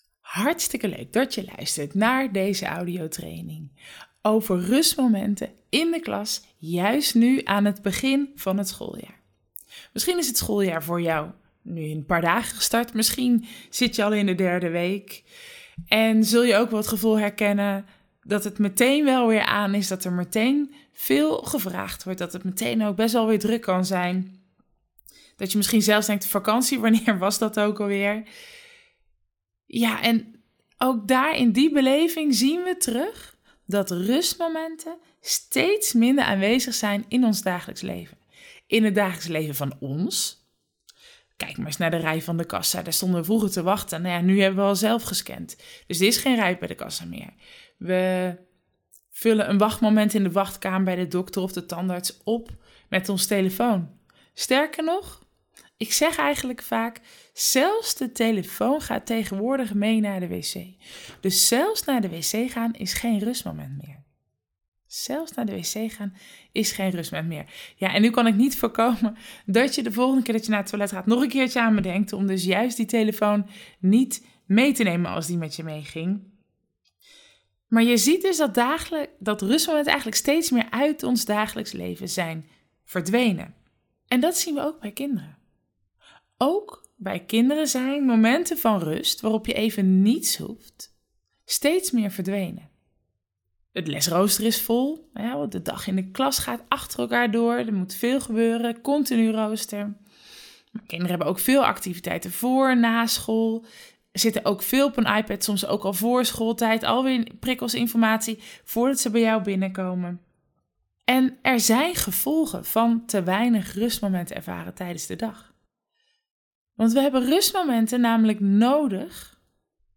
Mini Audiotraining: Ontdek in 25 minuten wat rustmomenten in de klas kunnen doen, juist nu aan het begin van het schooljaar